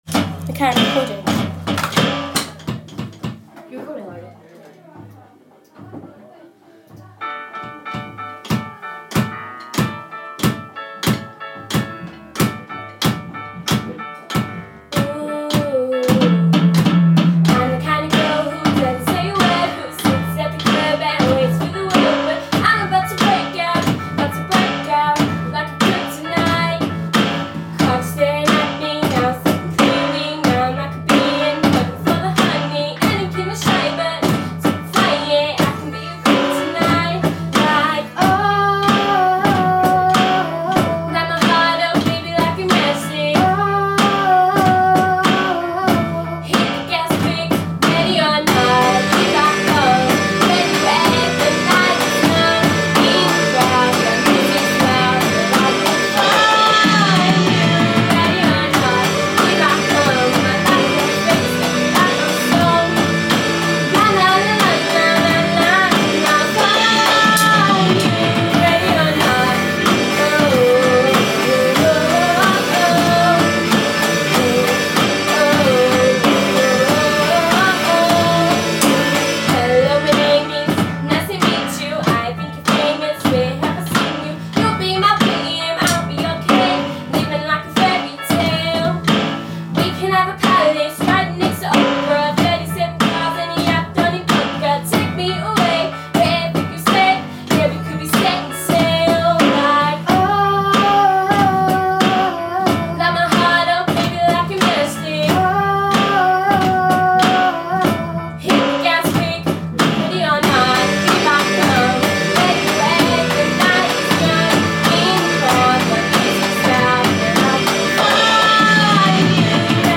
Drum and singing